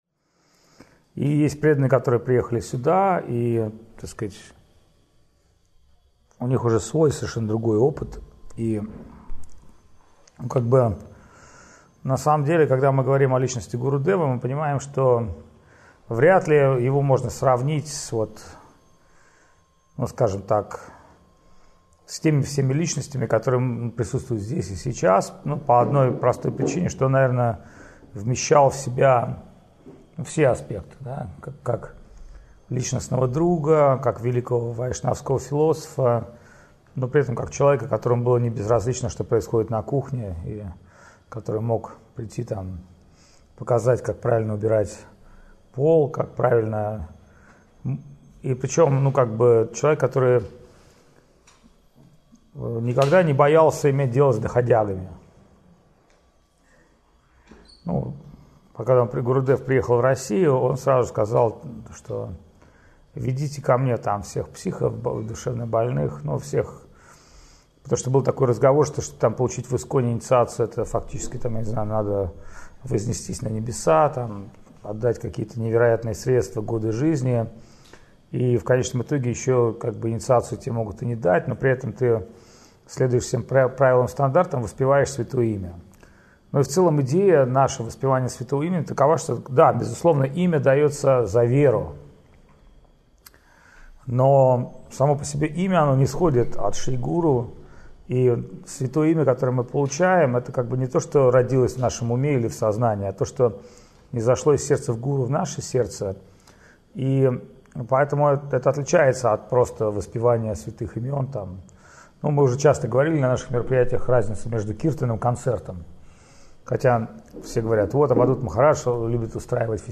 День Ухода Шрилы Б. С. Говинды Махараджа. Беседа